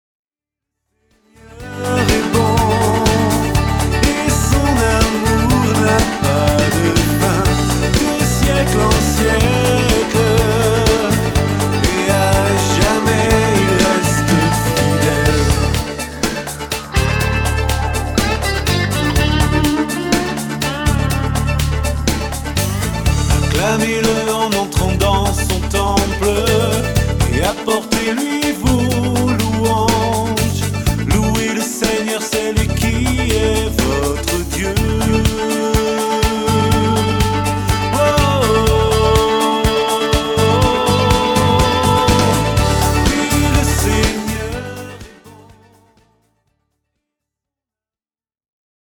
Chants de louange originaux interprétés par leurs auteurs